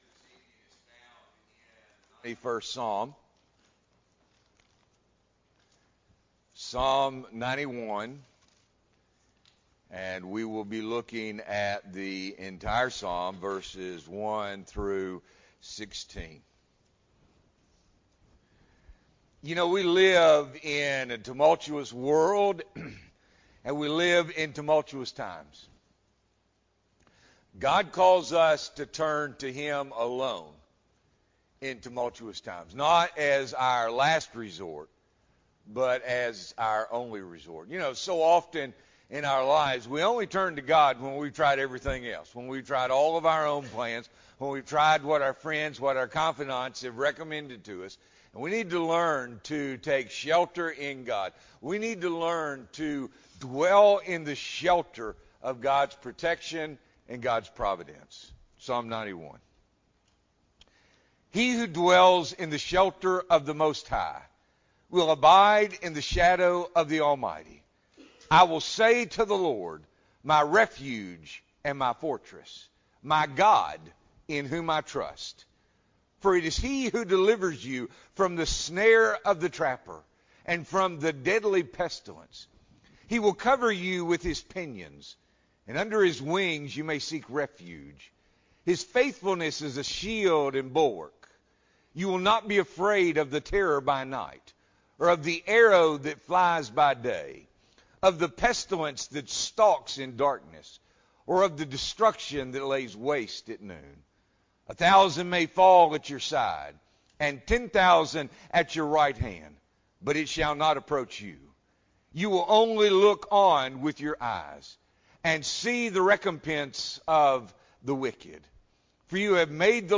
September 12, 2021 – Evening Worship